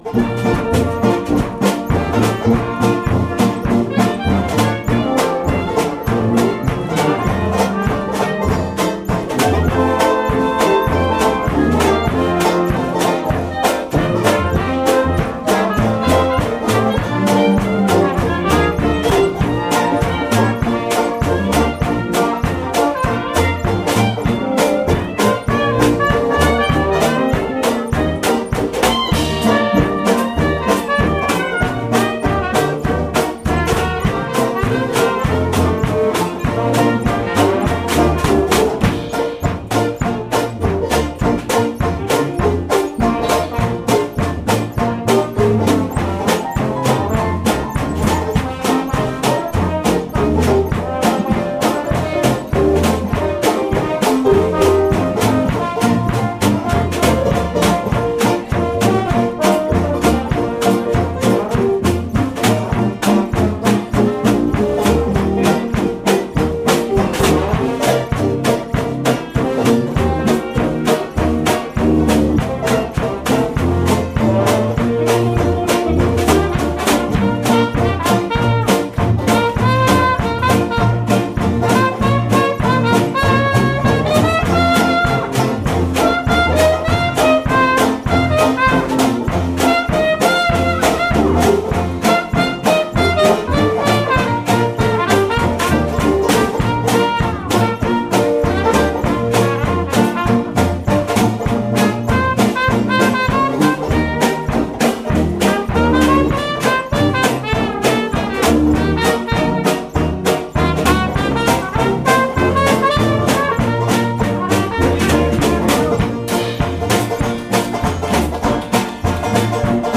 Banjo
Clarinet
Cornet
Drums
Guitar
Piano
Trombone
Trumpet
Tuba
Washboard